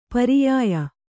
♪ pariyāya